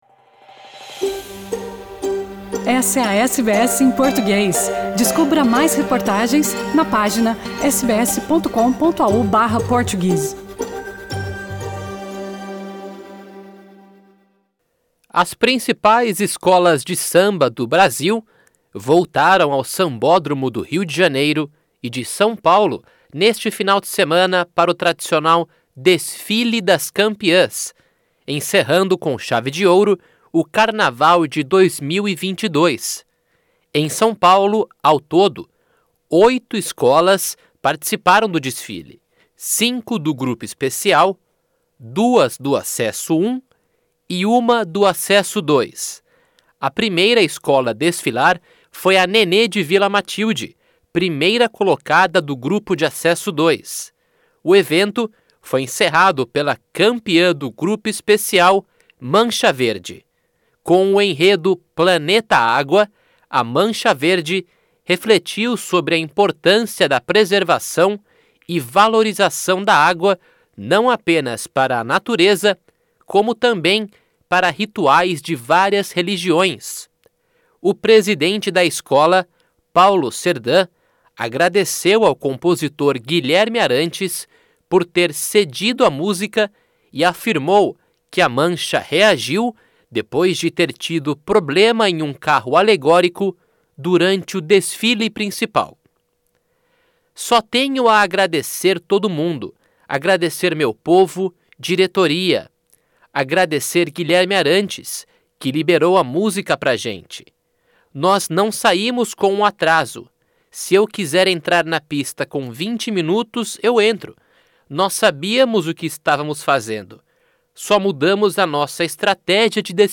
Bateria 61 durante ensaio em Sydney. Source: SBS